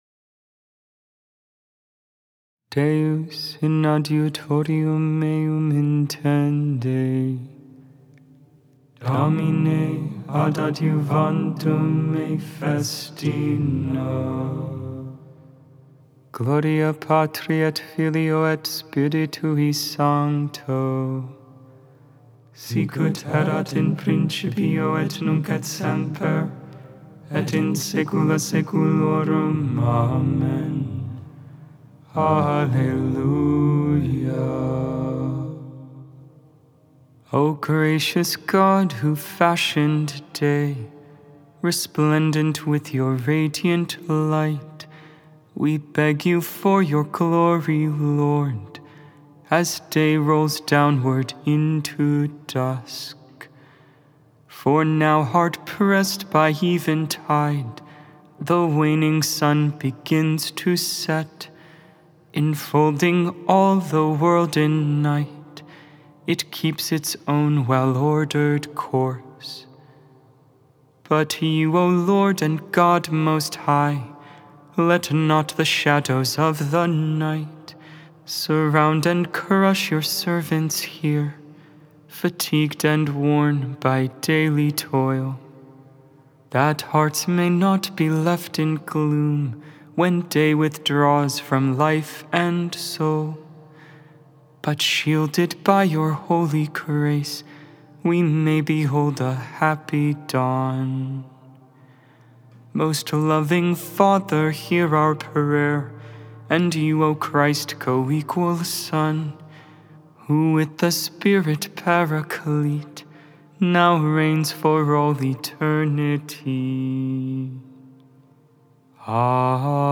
10.17.24 Vespers, Thursday Evening Prayer